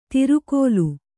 ♪ tirukōlu